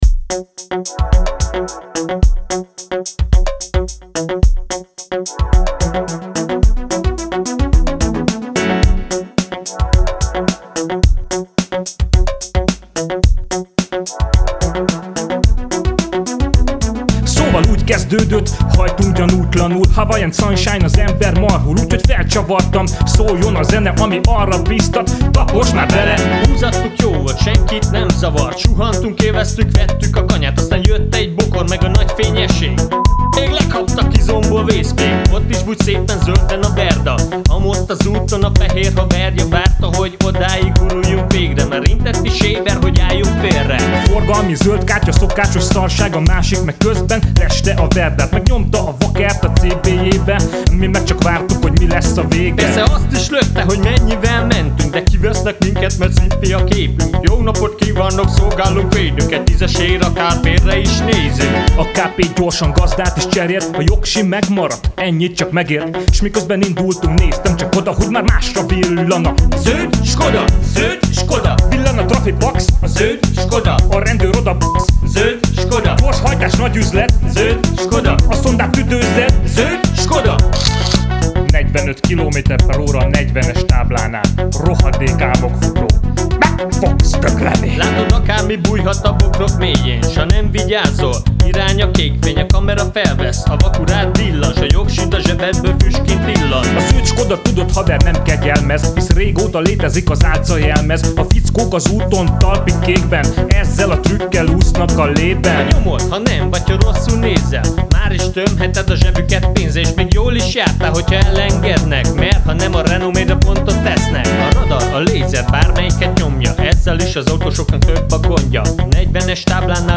(Ez itt a cenzúrázott változat, az illem kedvéért :)